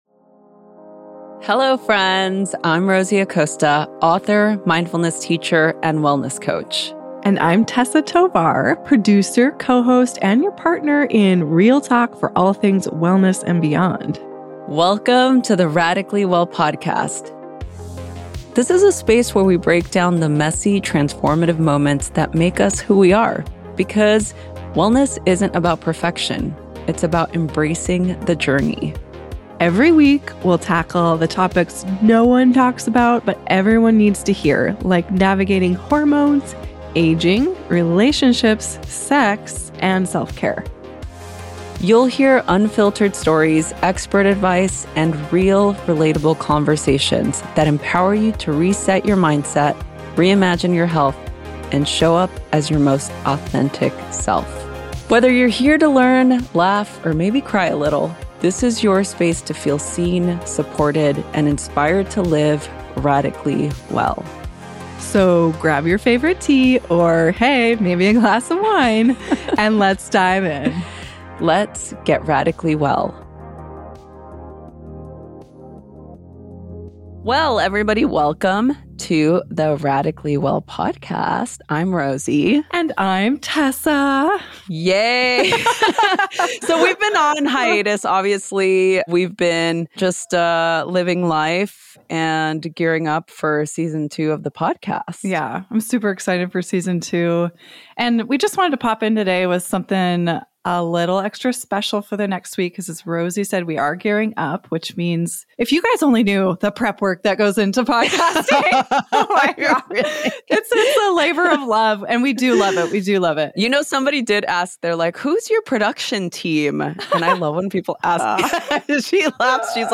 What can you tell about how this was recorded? This mash-up episode of Radically Well brings together bold, honest reflections from Season 1—centering on communication, emotional health, and owning your voice in both relationships and professional life.